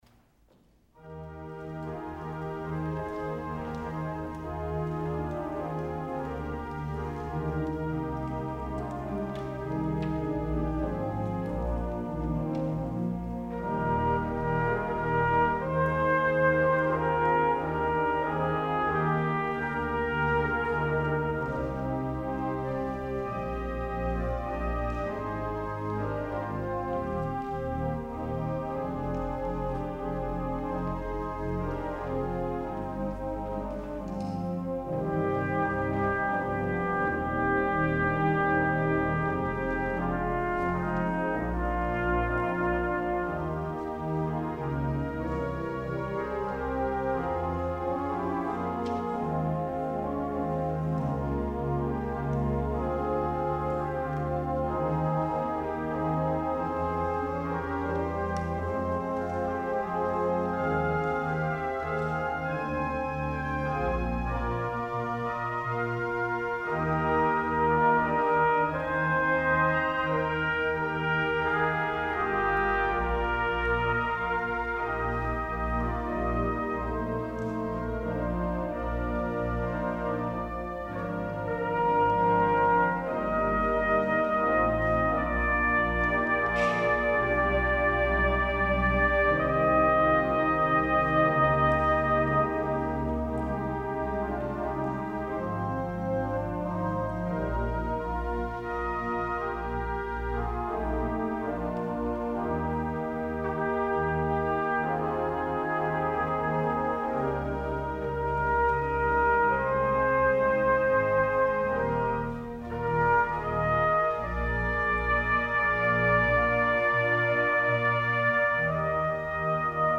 Selection:  Pastorale on a Theme of Palestrina (Paschal suite for Trumpet and Organ), Richard Webster
trumpet
organ